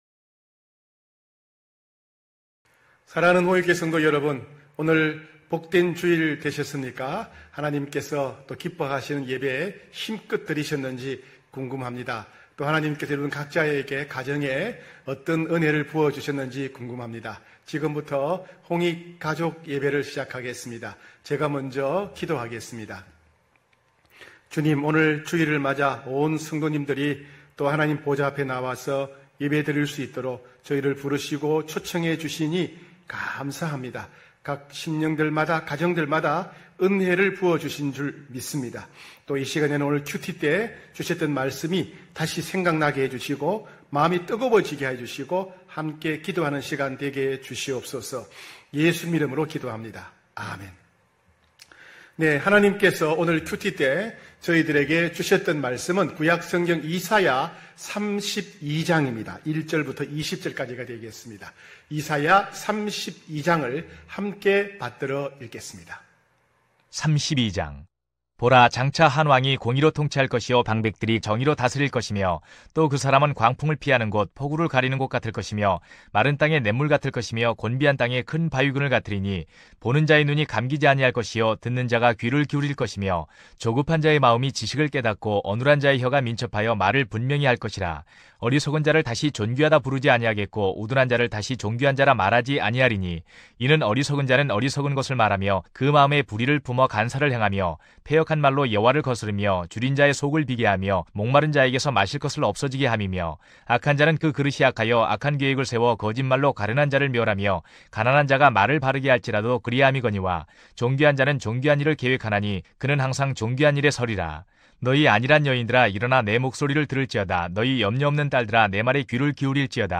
9시홍익가족예배(8월23일).mp3